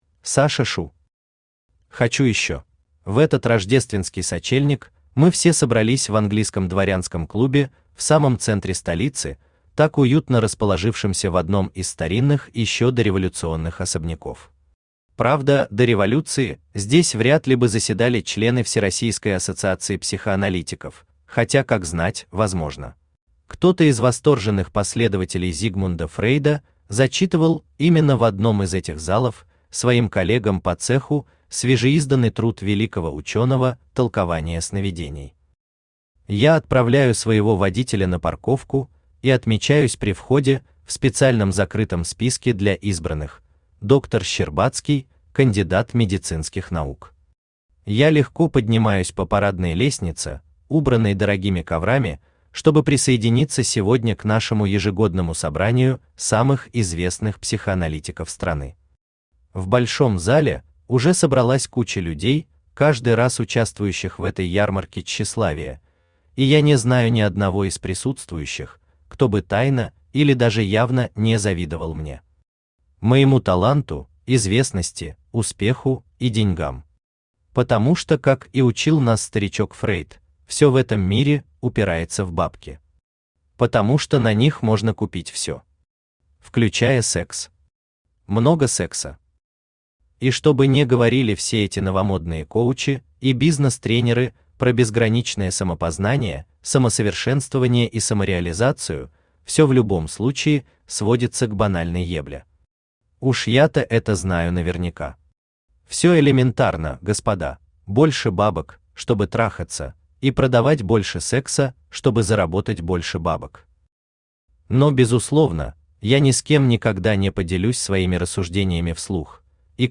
Aудиокнига Хочу ещё Автор Саша Шу Читает аудиокнигу Авточтец ЛитРес.